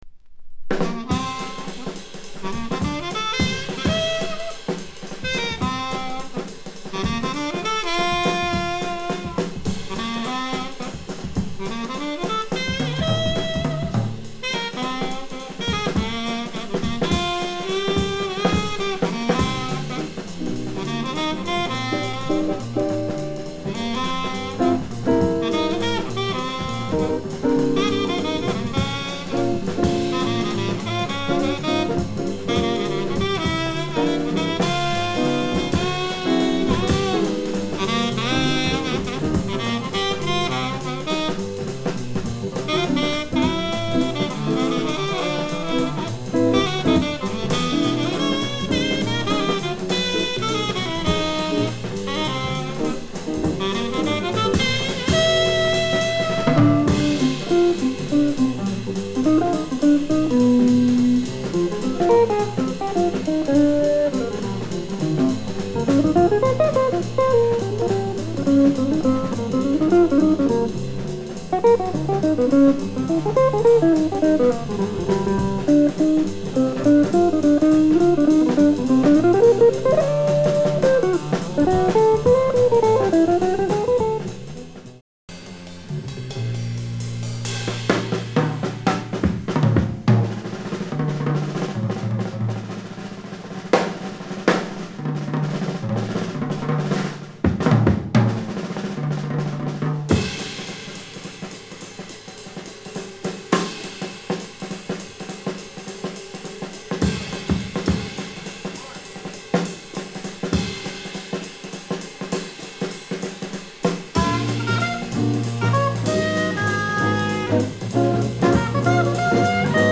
discription:Stereo